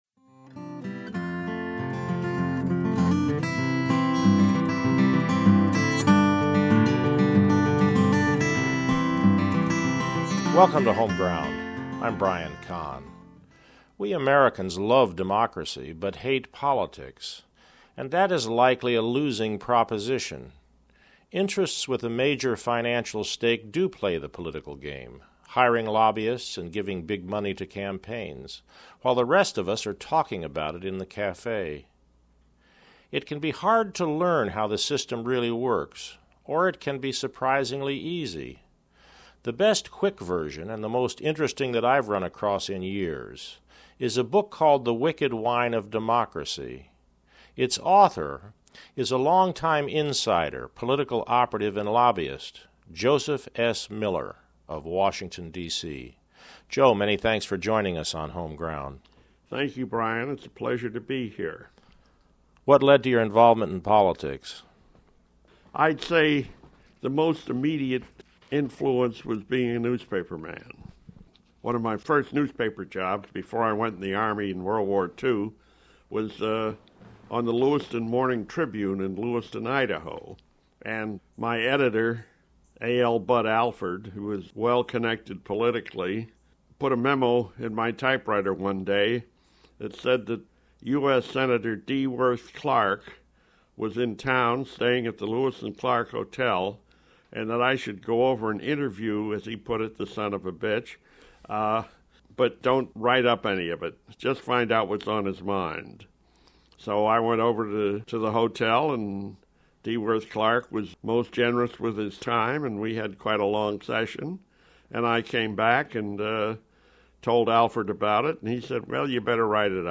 Audio Interviews